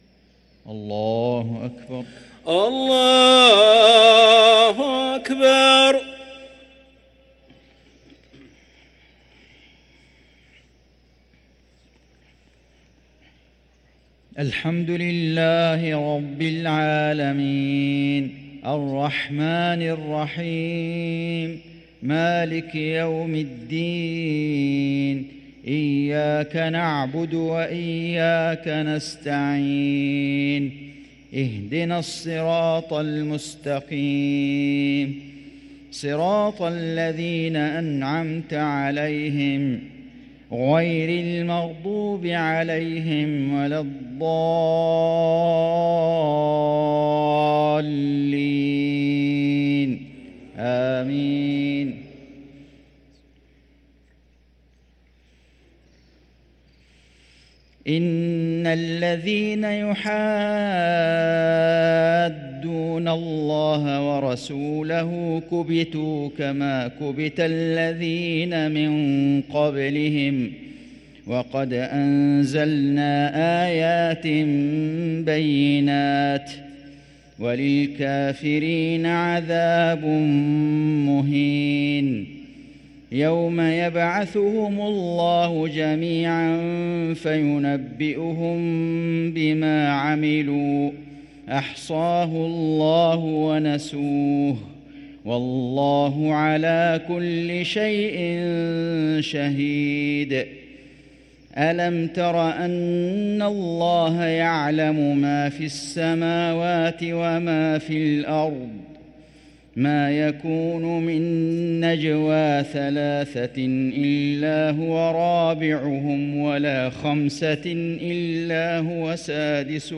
صلاة المغرب للقارئ فيصل غزاوي 12 شعبان 1444 هـ
تِلَاوَات الْحَرَمَيْن .